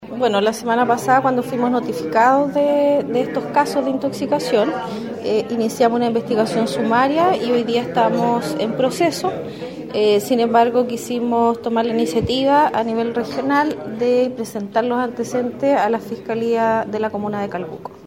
Sofia Torres, seremi de Salud subrrogante, proporcionó detalles de los antecedentes entregados en la Fiscalía de Calbuco.